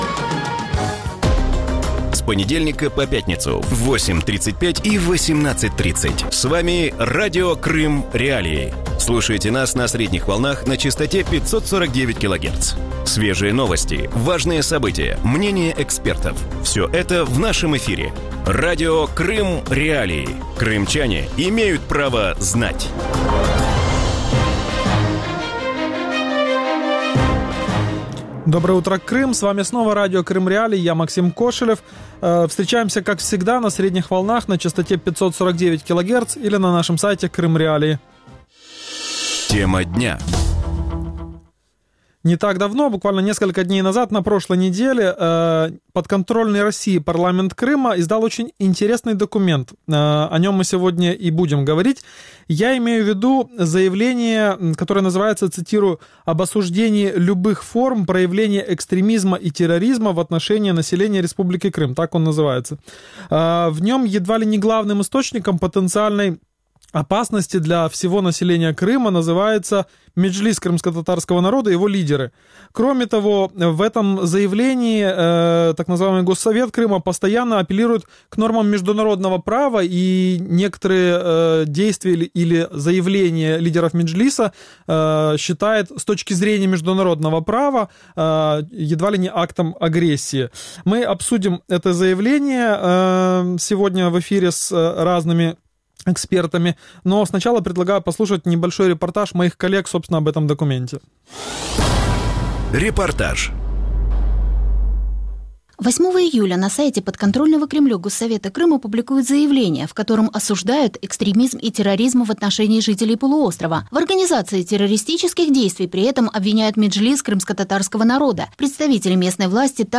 Утром в эфире Радио Крым.Реалии говорят об обвинениях Меджлиса крымскотатарского народа в организации терроризма. Что стоит за заявлением подконтрольного Кремлю Госсовета Республики Крым? И насколько корректно для властей аннексированной территории ссылаться на международное право?